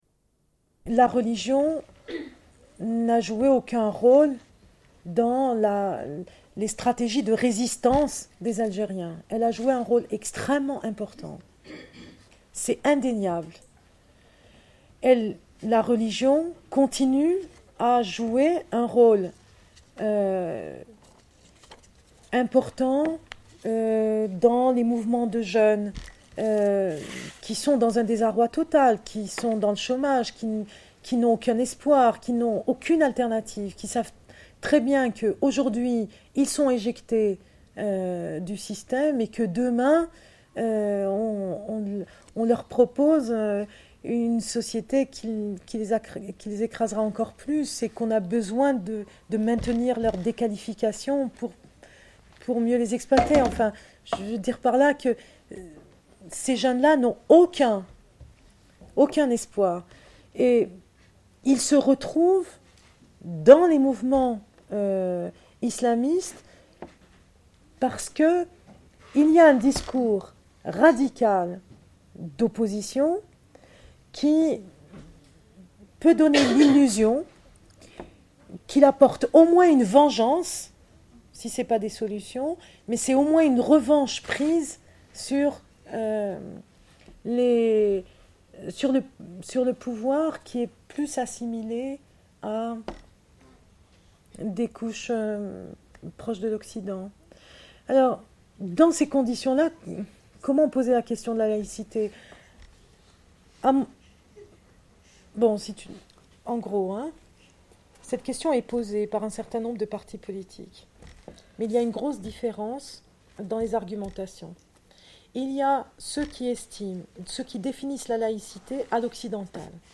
Une cassette audio, face B31:44
Rush Enregistrement sonore